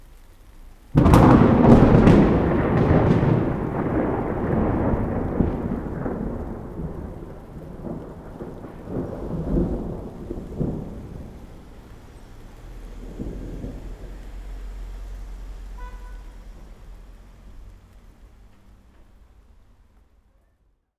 enviro_thunder_5.wav